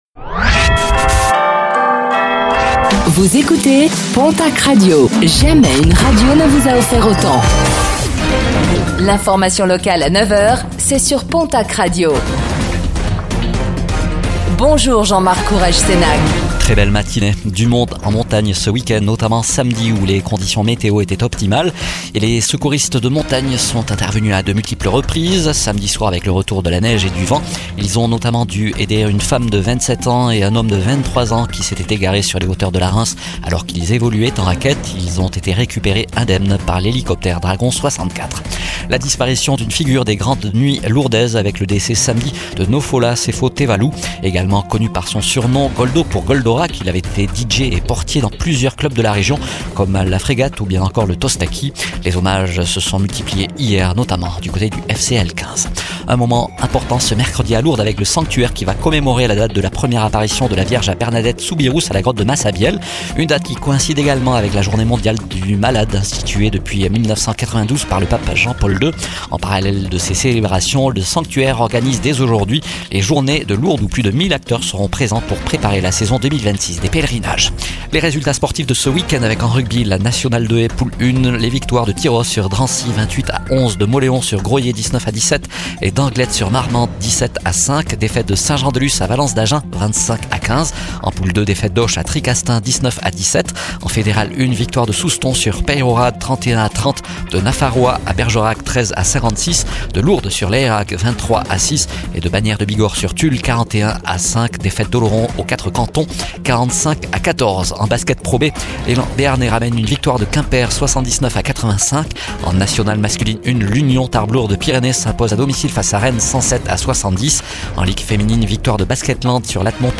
Infos | Lundi 09 février 2026